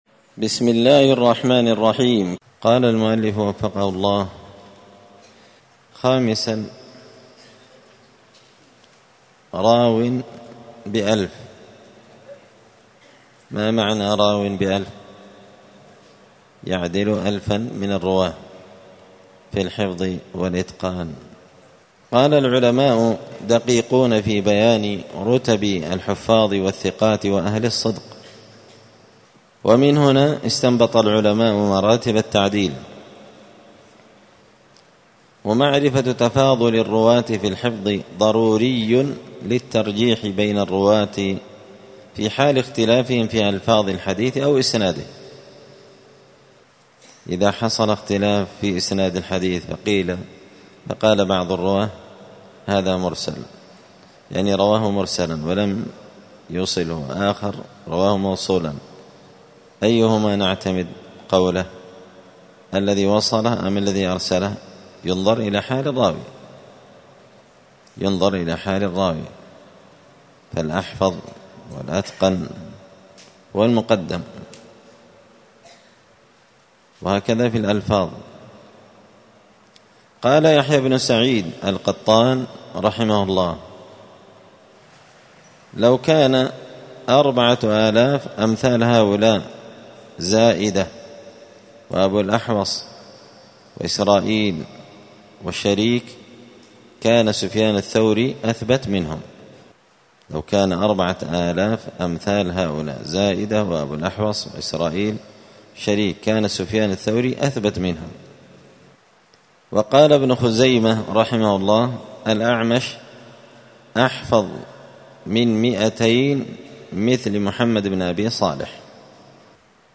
*الدرس السادس عشر (16) راو بألف راوي*